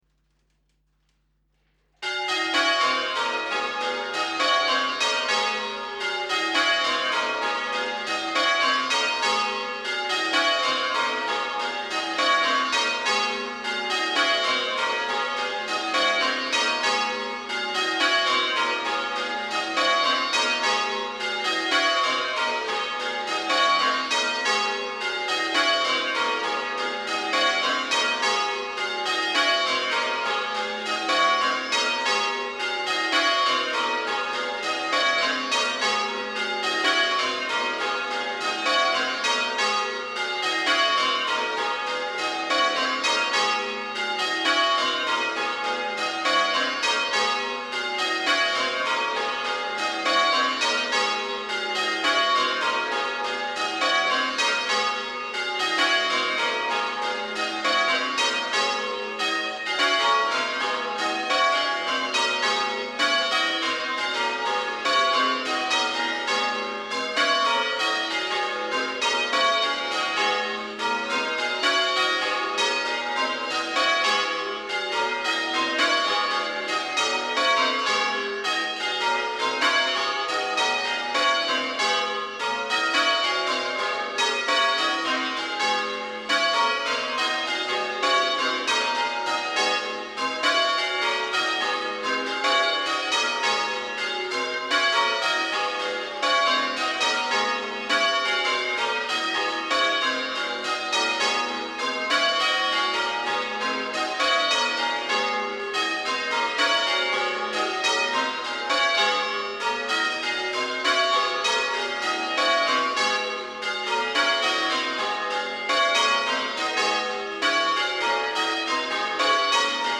The contest is open to bands comprising ringers aged under 19 on 31st August 2021.
Method Ringing (All Saints), competing for the Whitechapel Trophy